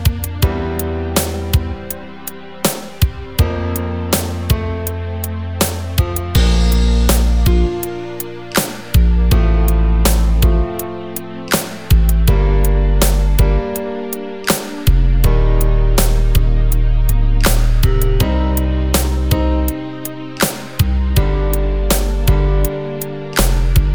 Two Semitones Down Soul / Motown 4:21 Buy £1.50